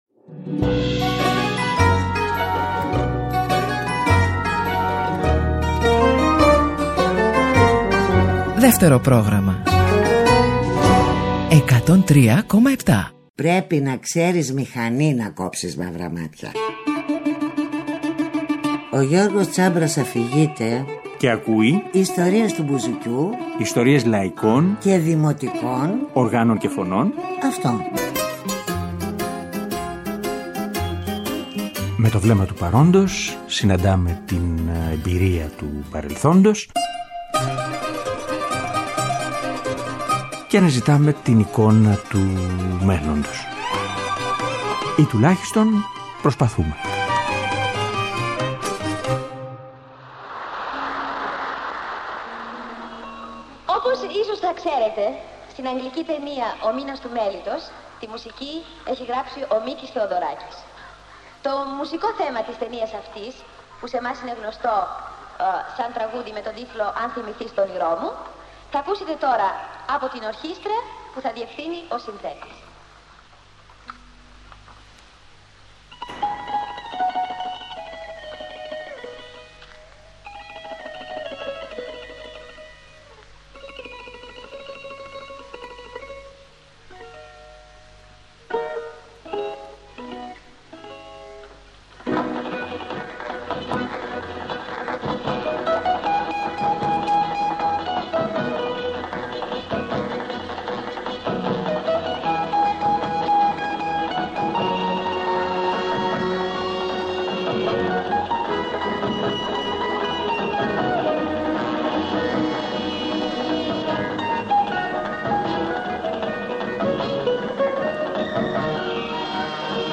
Στις δύο εκπομπές ωστόσο, θα ακούσουμε τον Μανώλη Χιώτη να παίζει μπουζούκι – τετράχορδο αλλά και τρίχορδο – σε τραγούδια άλλων δημιουργών.
Ο Μανώλης Χιώτης παίζει μπουζούκι σε τραγούδια άλλων δημιουργών